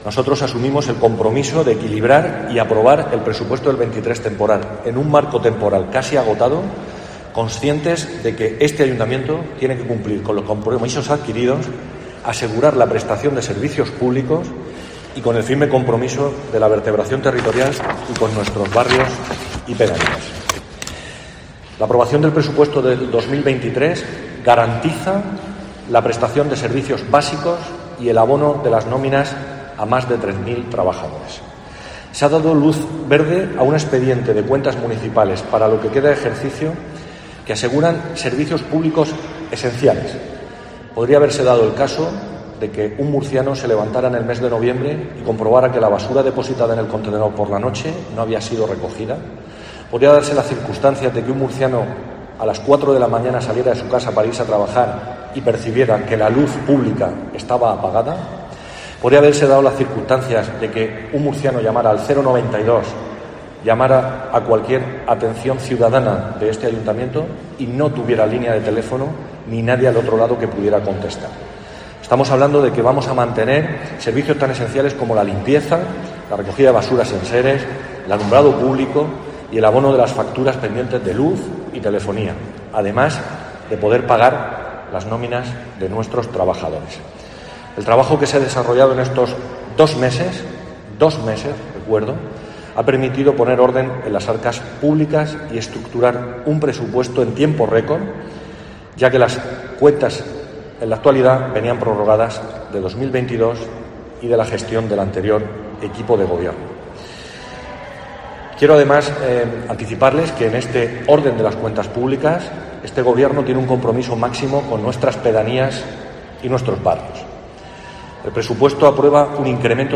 José Francisco Muñoz, concejal de Movilidad, Gestión Económica y Contratación